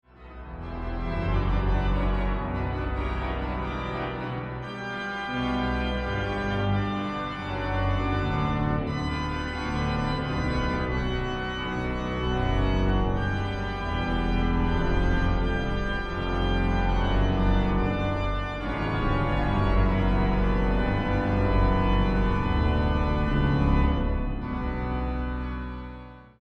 Builder: Willis (Father)Type: Historic English romantic organ
Location: Salisbury Cathedral, Salisbury, England